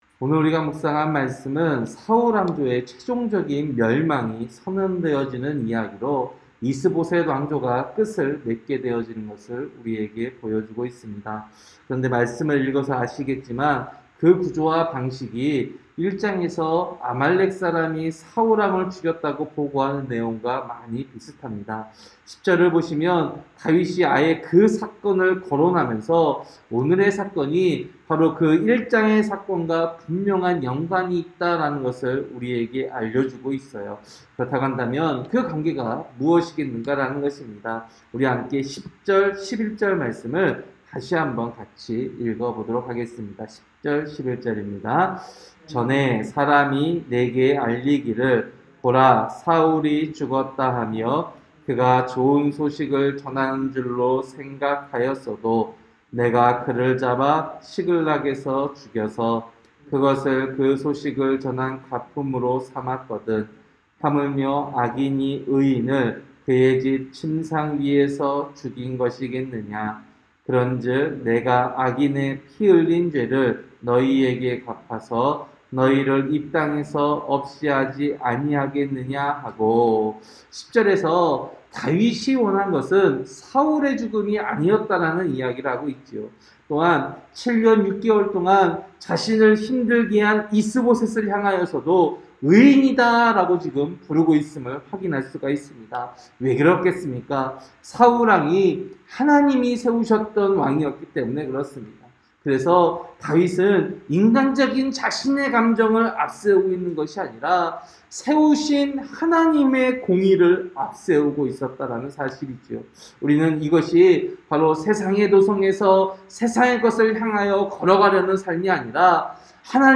새벽설교-사무엘하 4장